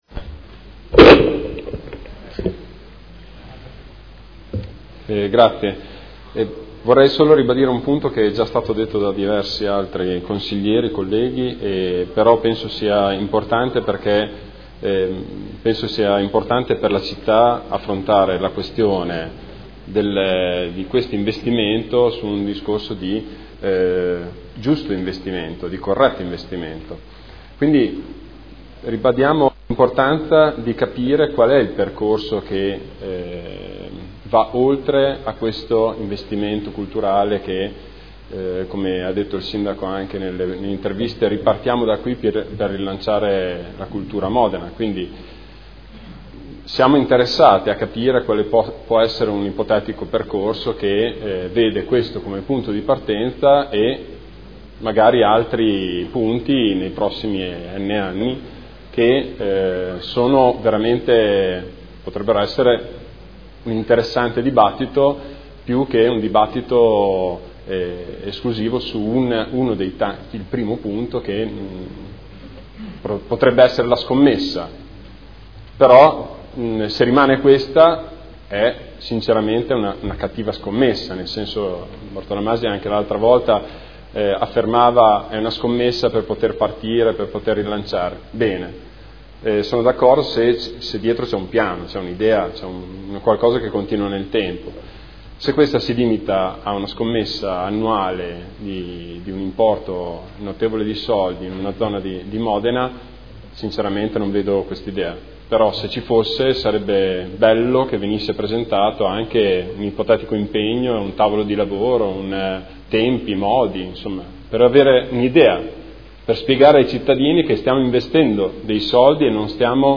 Seduta del 09/07/2015 Dibattito. Interrogazioni 81876, 83091, 85381 presentate da Scardozzi e Galli su Manifattura e mostre.